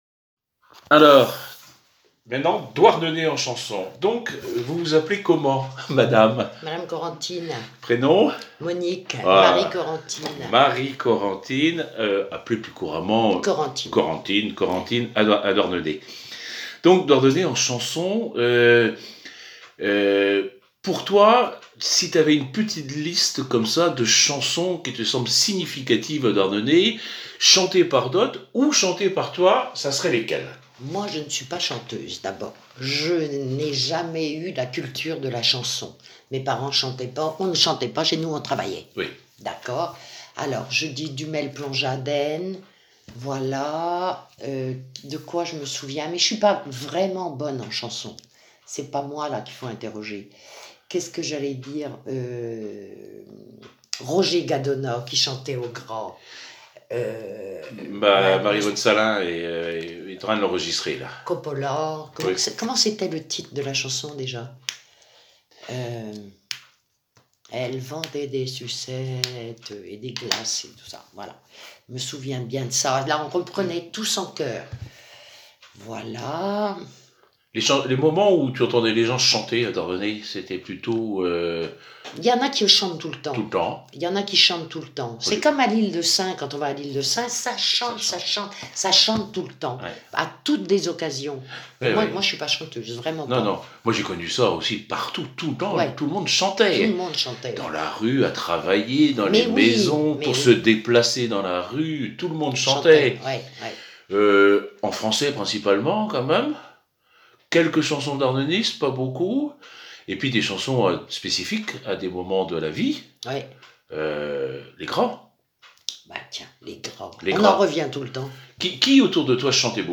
Enquête Douarnenez en chansons
Catégorie Témoignage